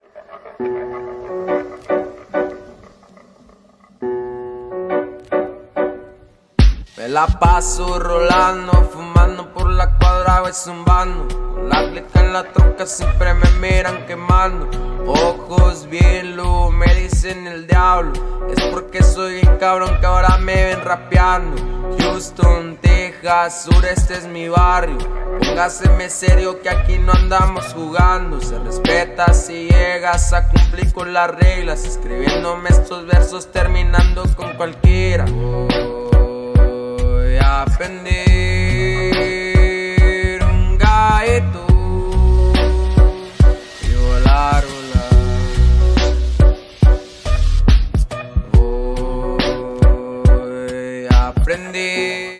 • Качество: 128, Stereo
мужской голос
забавные
спокойные
пианино
медленные